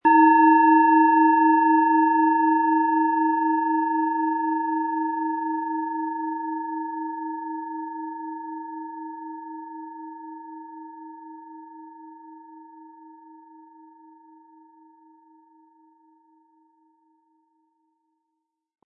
Antike Assam-Klangschale – Ruhe finden & dem inneren Rhythmus folgen
Ihr Klang berührt tief – sanft, aber durchdringend – und schenkt dir Momente der Einkehr, der Verbindung und des inneren Gleichgewichts.
Der tiefe, warme Ton entfaltet sich leicht – und begleitet dich wie ein stiller Freund in die Ruhe.
Im Sound-Player - Jetzt reinhören können Sie den Original-Ton genau dieser Schale anhören.
PlanetentöneWasserstoffgamma & Mond & Neptun (Höchster Ton)
MaterialBronze